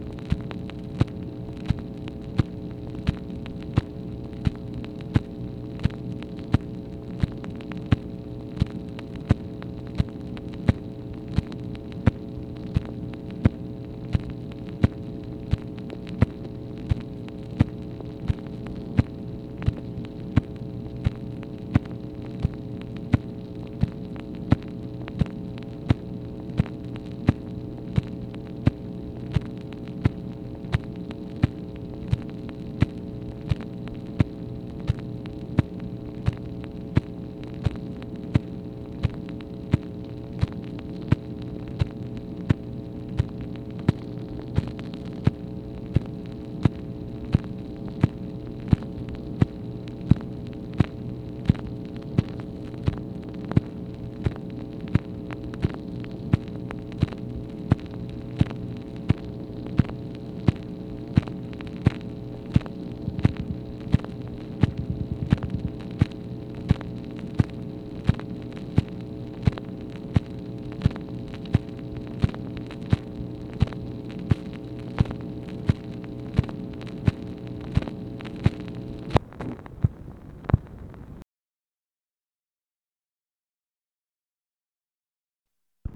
MACHINE NOISE, August 20, 1965
Secret White House Tapes | Lyndon B. Johnson Presidency